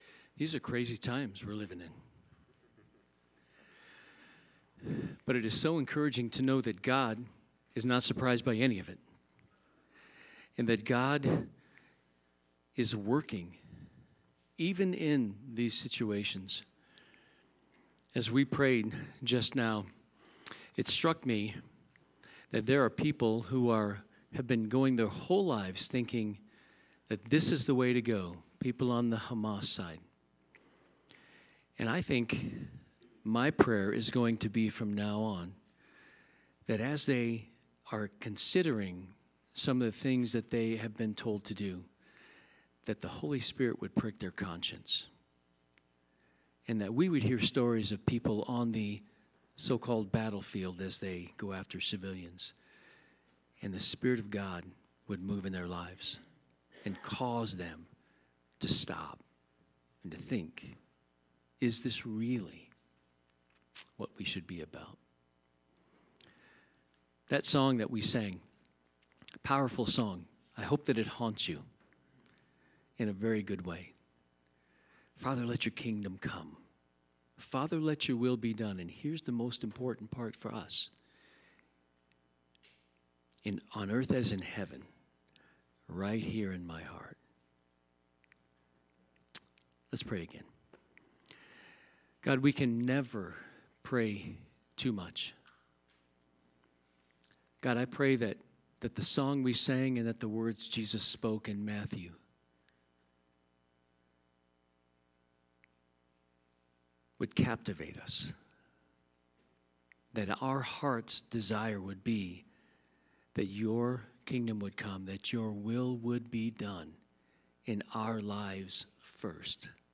Sermons | First Baptist Church of Golden